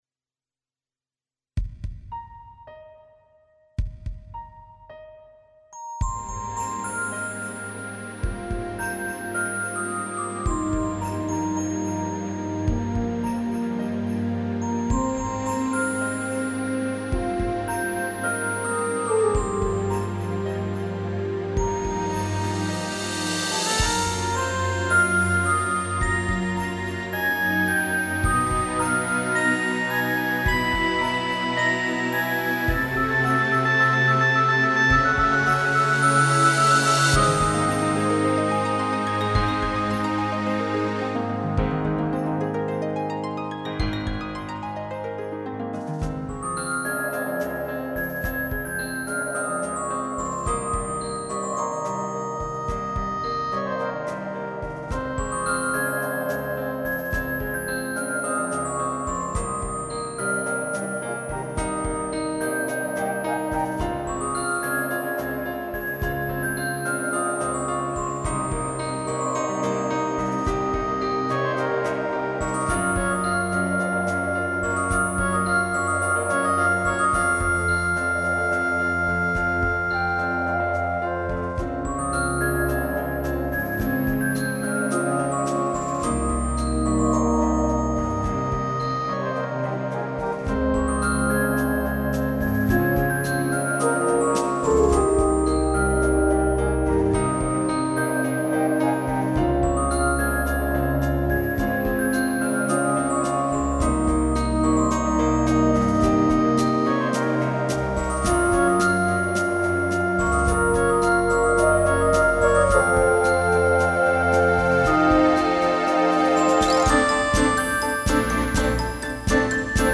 浮遊感の漂う曲。